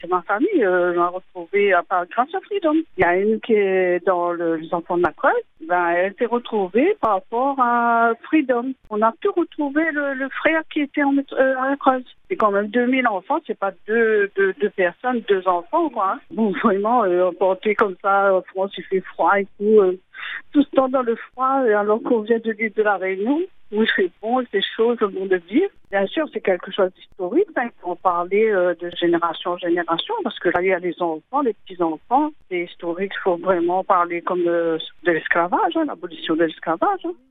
Dans son témoignage, cette auditrice raconte le rôle déterminant de Free Dom, qui a permis de retisser un lien familial perdu.